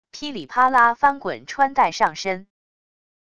噼里啪啦翻滚穿戴上身wav音频